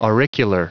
Prononciation du mot auricular en anglais (fichier audio)
Prononciation du mot : auricular